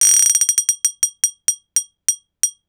bottle hit slowdown.wav